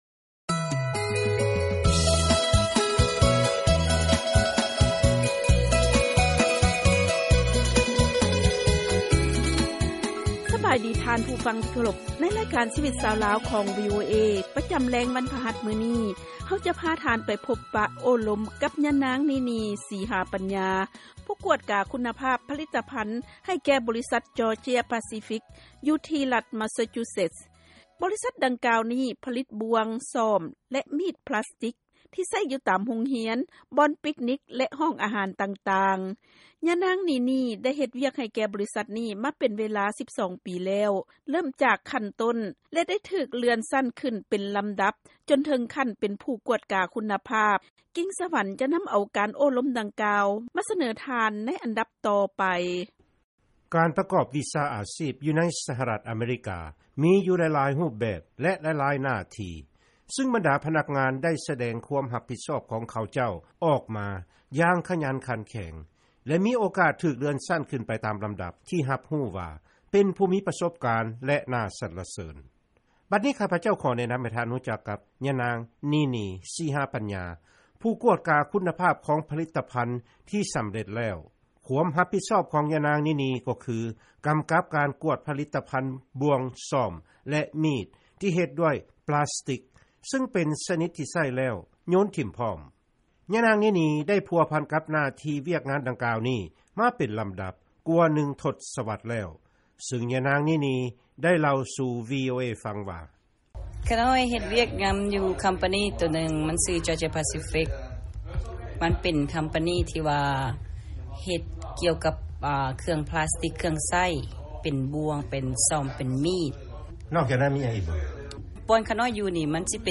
ເຊີນຟັງການຳສຳພາດ